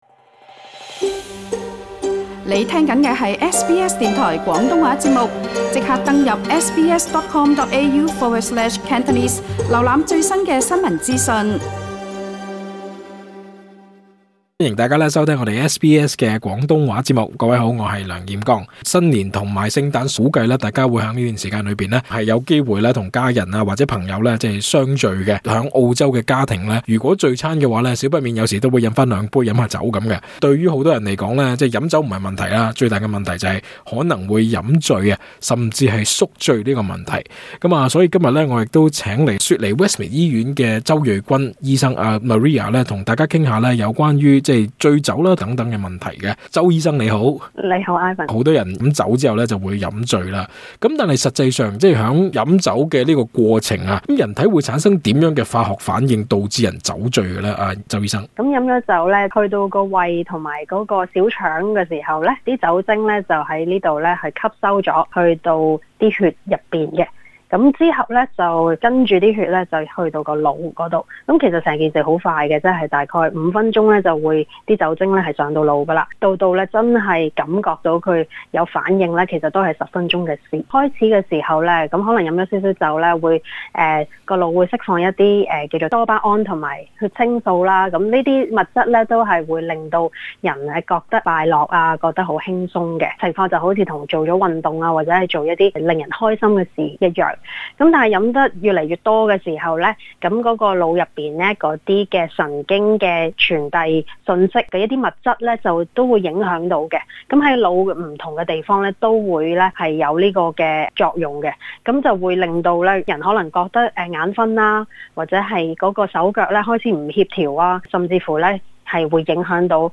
更多訪問內容，請收聽足本錄音。